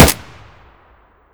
ak47_fp.wav